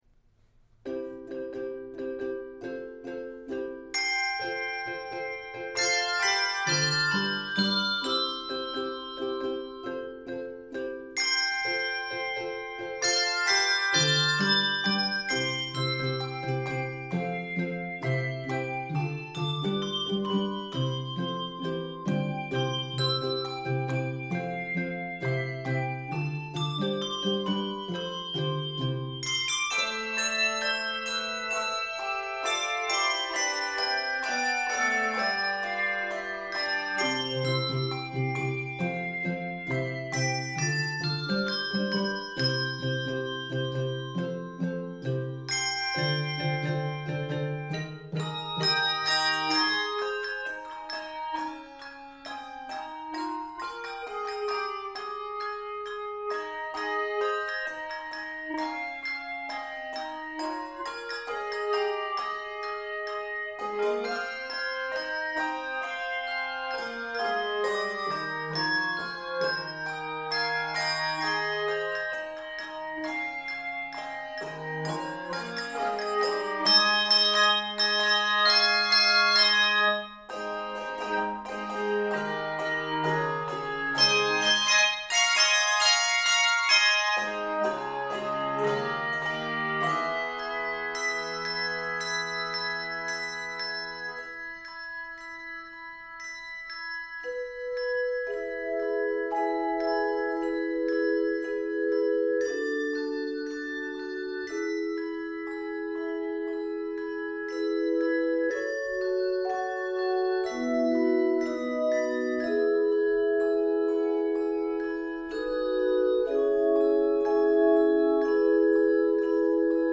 Keys of C Major, Ab Major, and E Major.